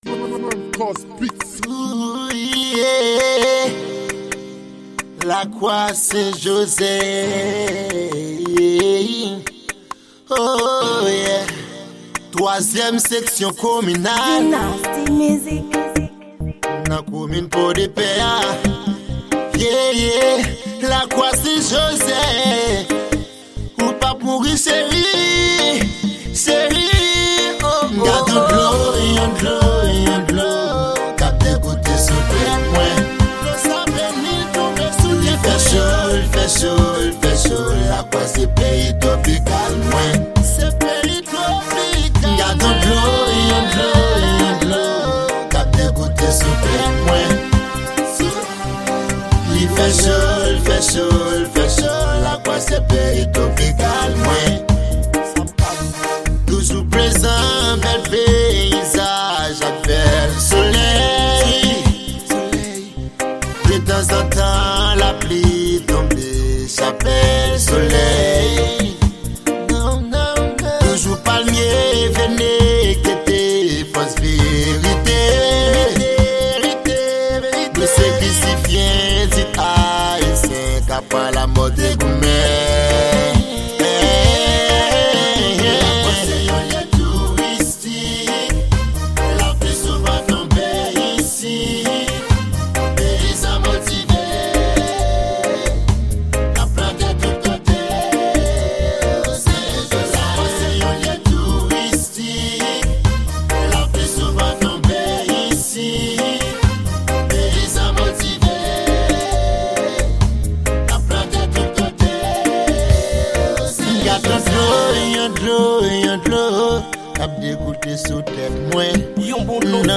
Genre: Rnb.